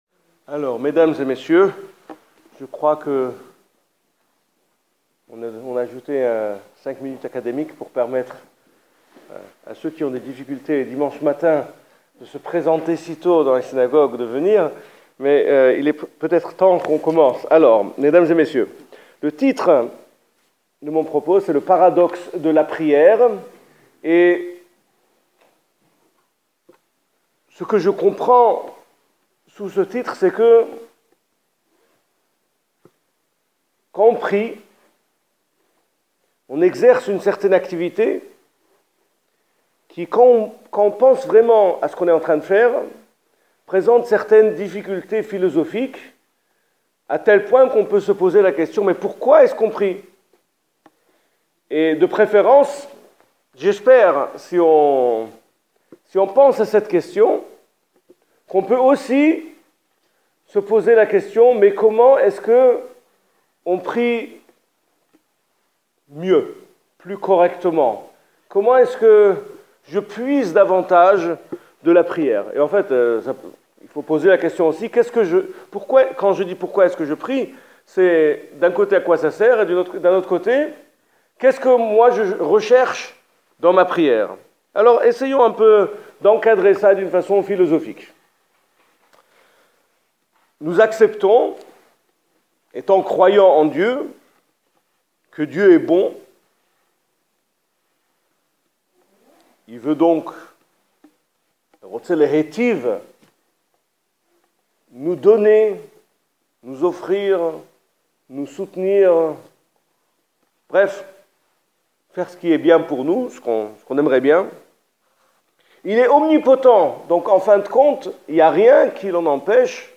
Cette conférence fût présentée à Strasbourg dans le cadre de la journée d'étude intitulée Vingt-cinq clefs du judaïsme, au sein…